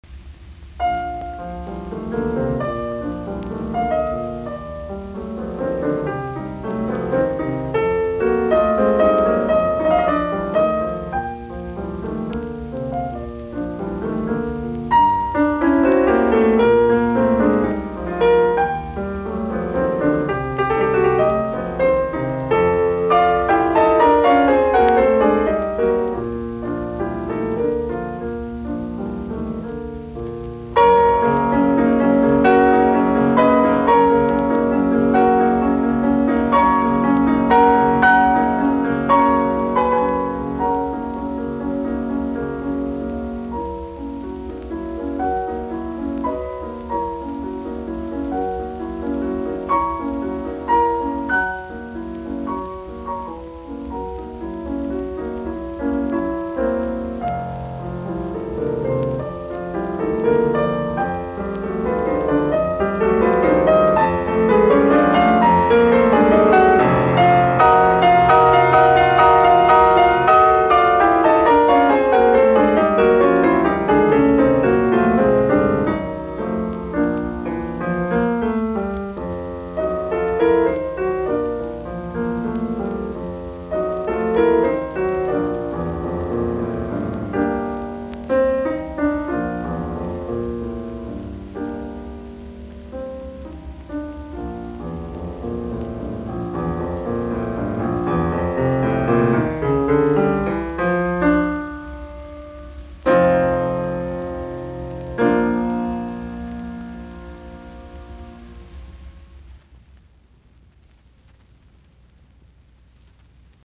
in B-flat major, cantabile